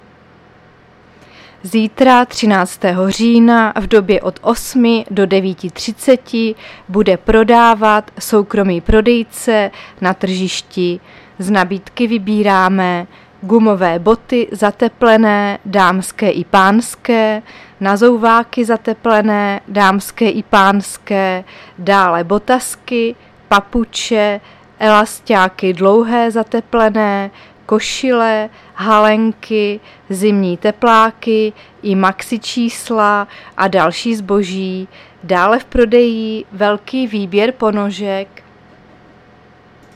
Záznam hlášení místního rozhlasu 12.10.2023